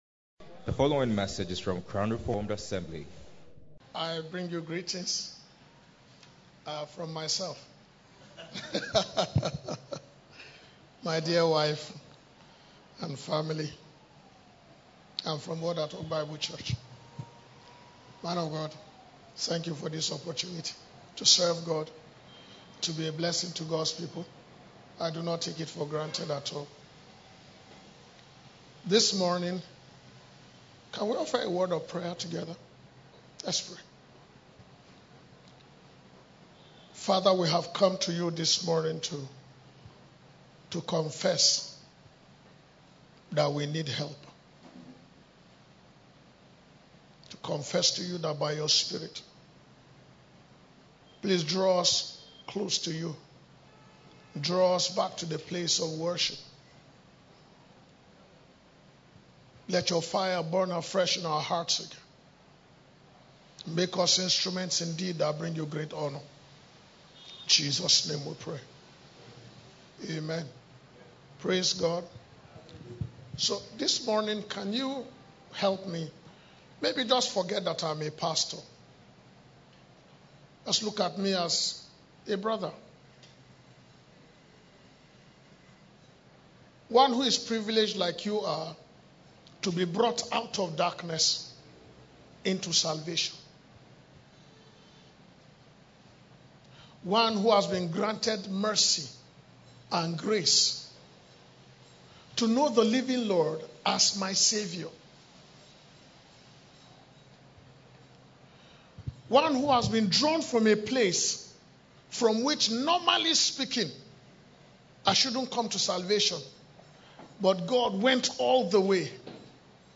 Series: Worker's Retreat - 2022